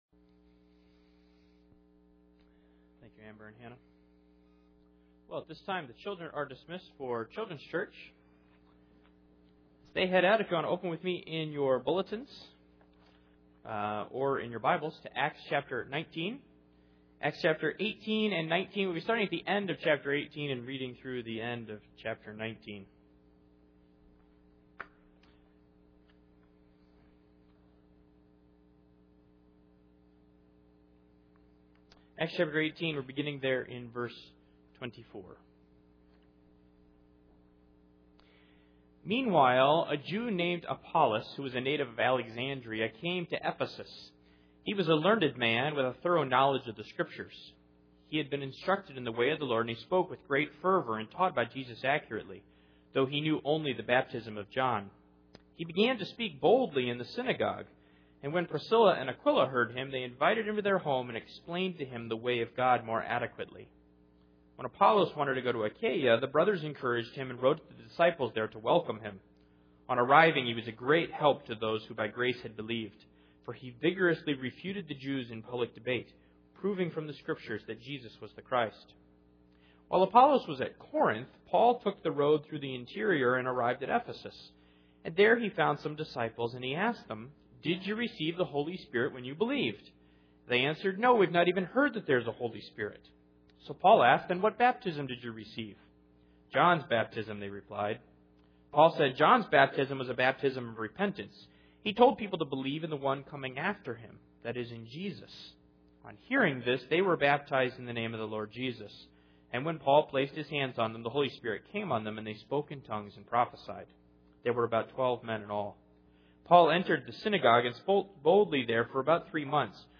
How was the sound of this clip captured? Acts 18:24-19:41 Service Type: Sunday Morning Luke presents three people or groups of people who thought they knew Jesus Christ.